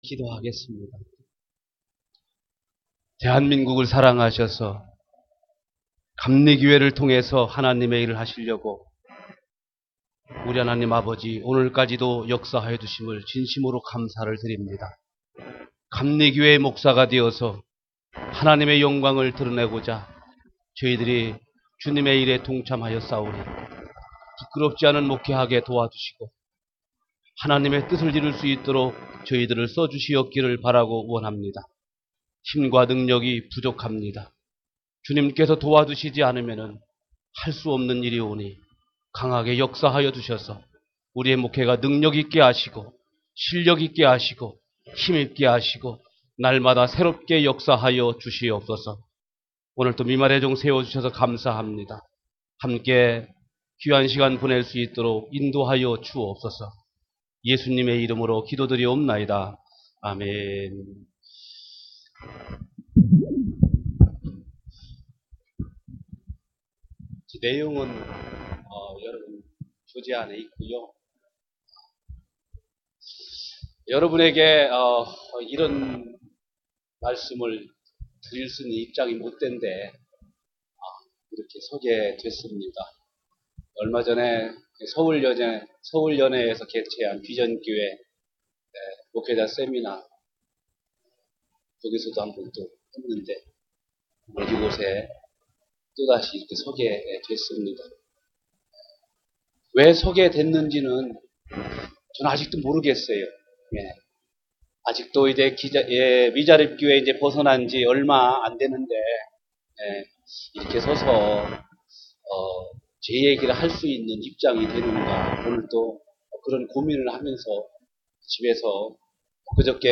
2012년 제5차 신바람목회 세미나 파일 10